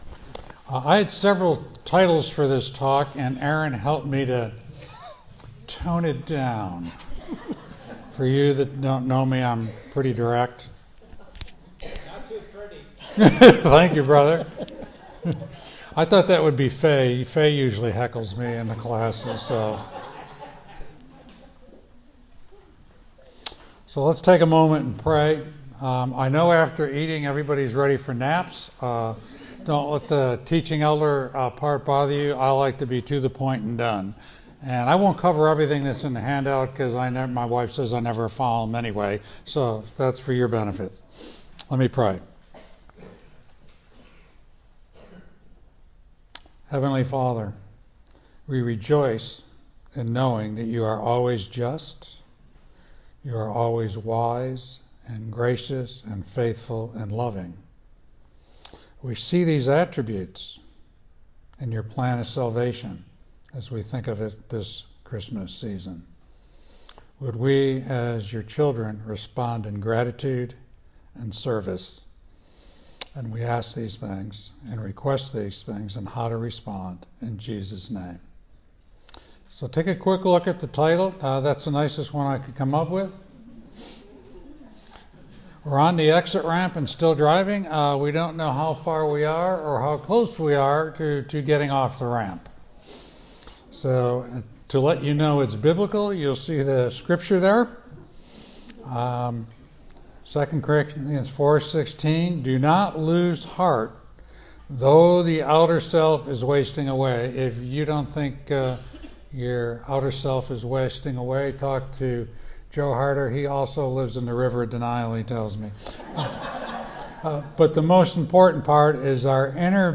Seniors’ Luncheon